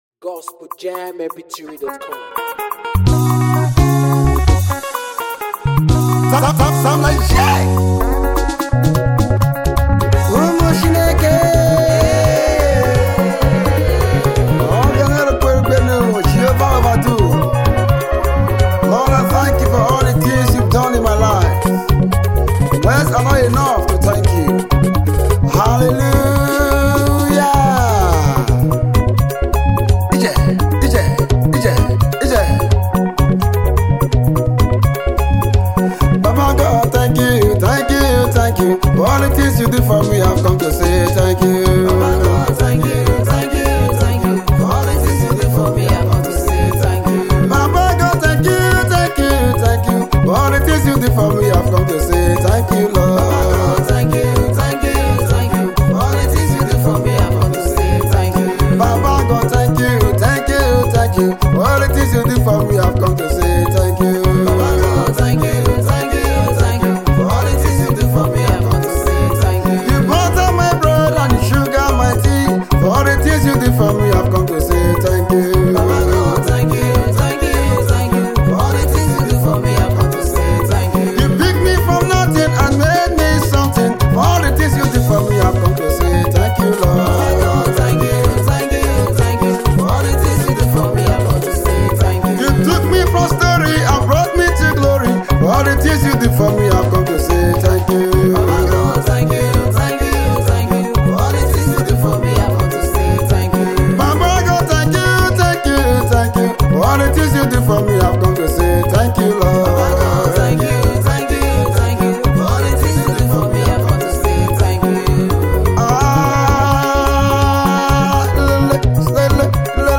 is a Nigerian gospel singer